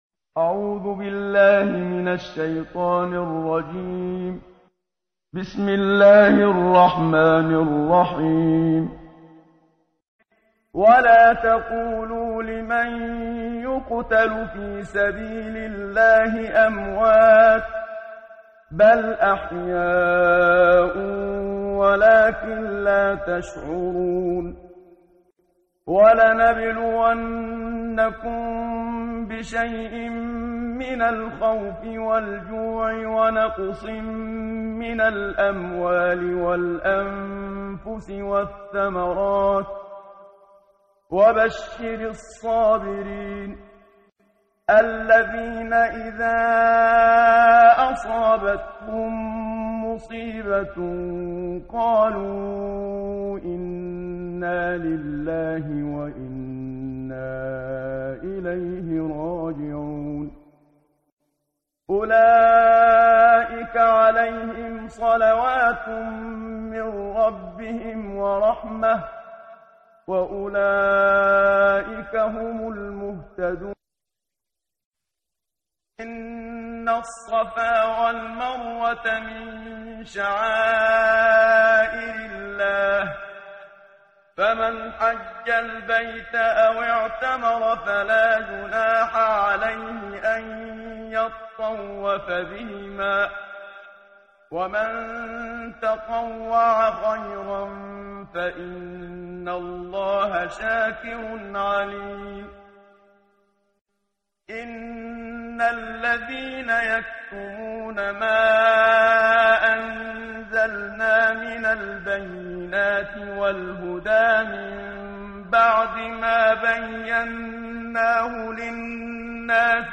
قرائت قرآن کریم ،امروز، صفحه 24، سوره مبارکه بقره آیات 146 تا 153 با صدای استاد صدیق منشاوی.